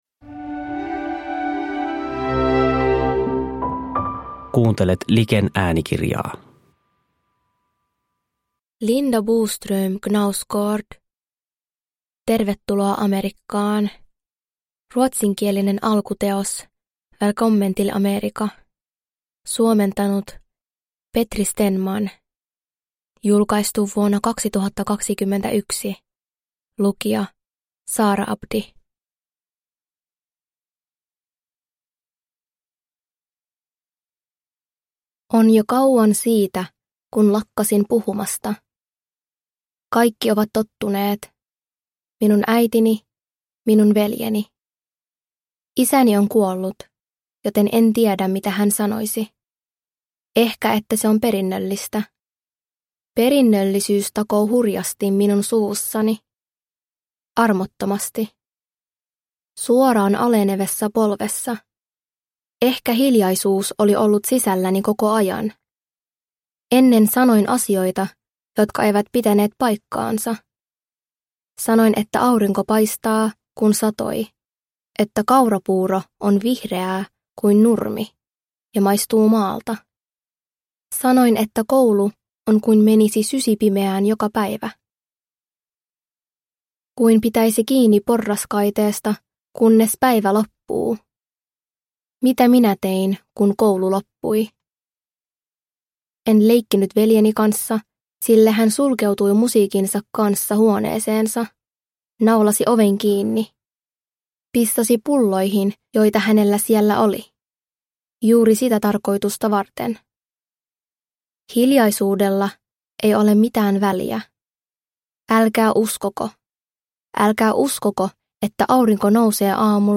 Tervetuloa Amerikkaan – Ljudbok – Laddas ner